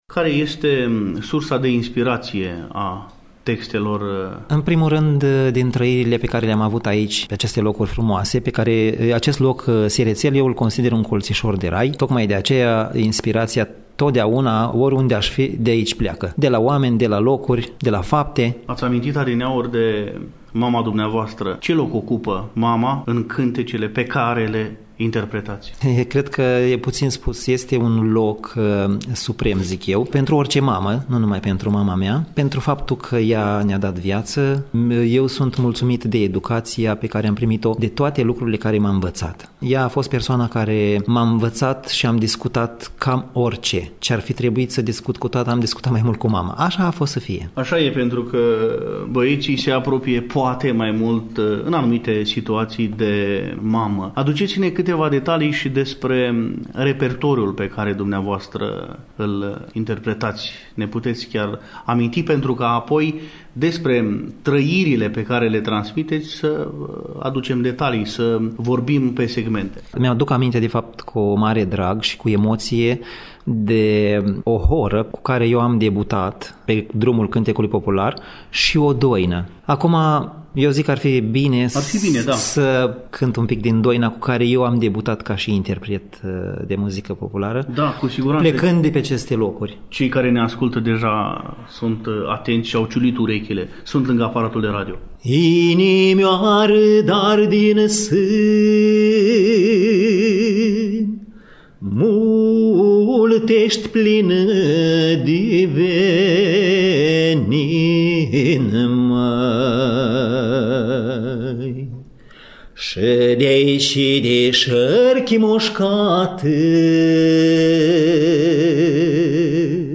Invitatul nostru ne spune care este sursa de ispirație a melodiilor populare pe care le interpretează – din  pruncie – amintindu-ne și care este locul Mamei în cântecele Domniei Sale, vorbindu-ne, cu emoție, despre debutul pe care l-a avut, încă de copil, conturându-ne, apoi, o imagine a repertoriului.